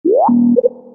На этой странице собраны звуки электронной почты: уведомления о новых письмах, отправке сообщений и другие сигналы почтовых сервисов.